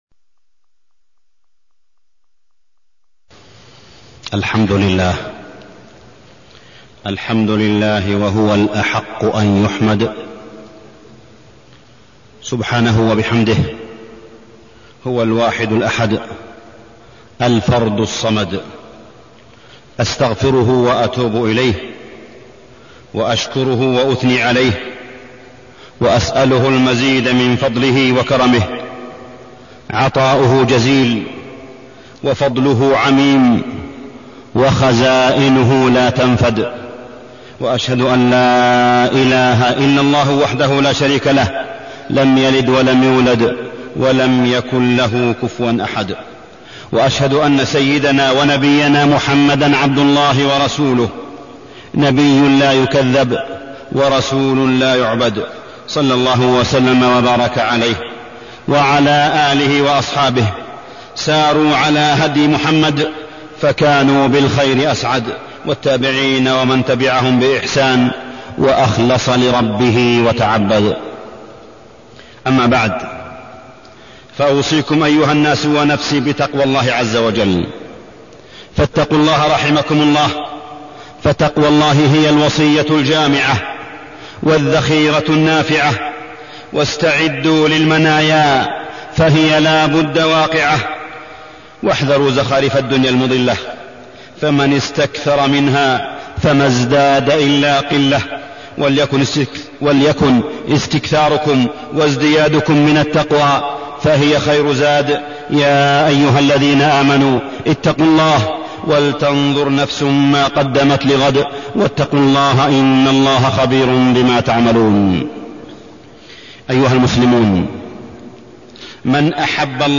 تاريخ النشر ٢٢ ربيع الثاني ١٤٢٢ هـ المكان: المسجد الحرام الشيخ: معالي الشيخ أ.د. صالح بن عبدالله بن حميد معالي الشيخ أ.د. صالح بن عبدالله بن حميد سيادة الأمة